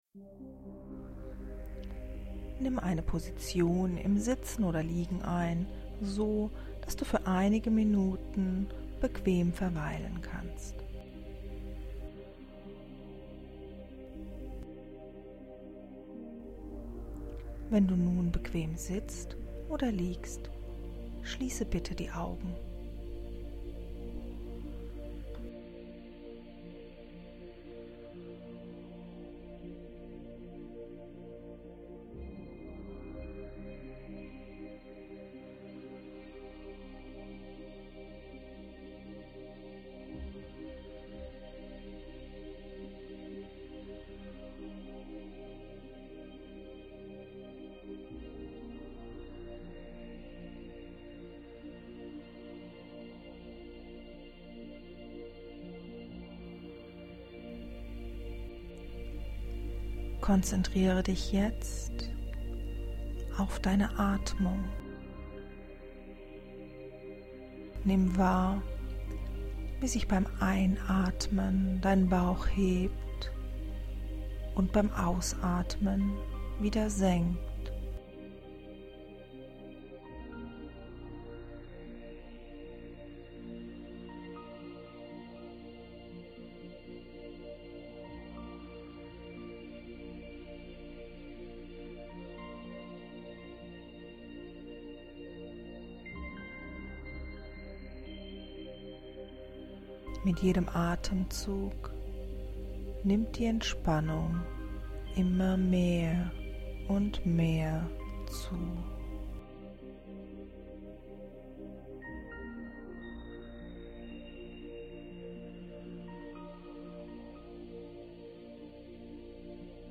Atemmeditation-mit-Musik.mp3